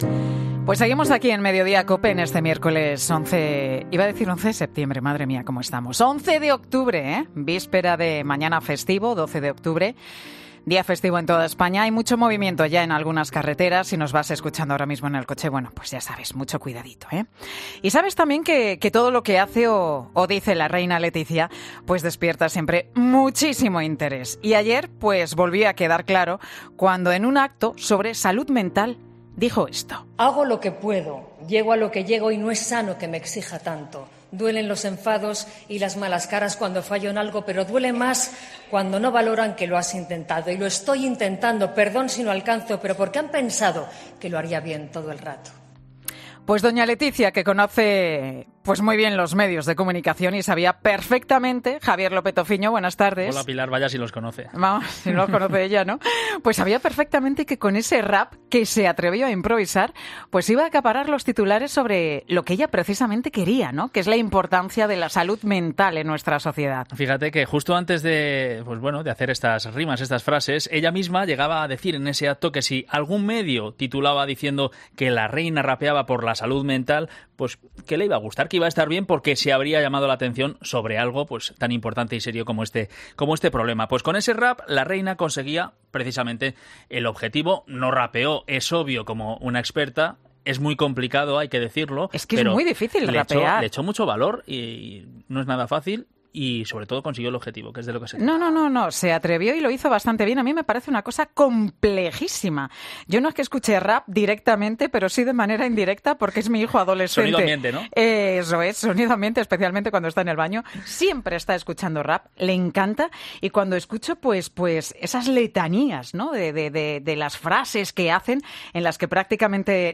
Escucha aquí la entrevista al completo de El Chojín en 'Mediodía COPE'. 00:00 Volumen Descargar Comparte en: Copiar enlace Descargar El Chojín revela lo que le dijo la Reina Letizia tras rapear una canción suya - El Chojín es afrodescendiente.